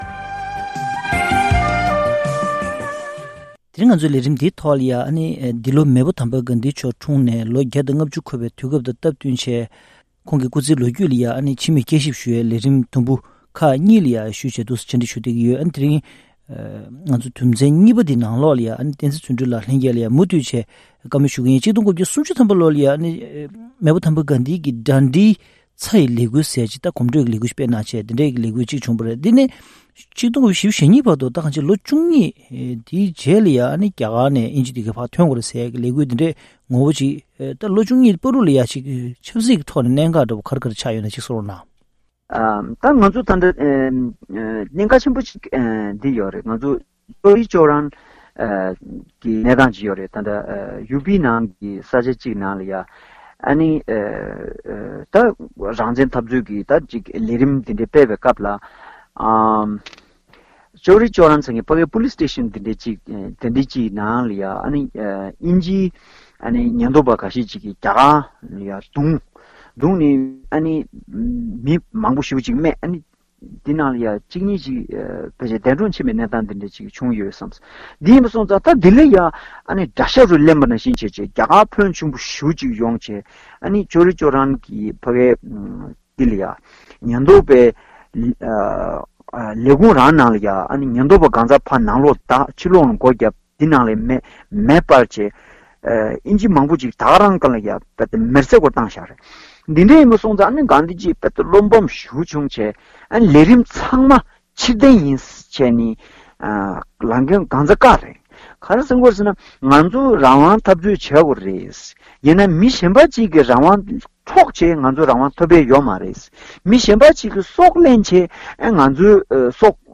༄༅༎གནད་དོན་གླེང་མོལ་གྱི་ལས་རིམ་ནང་།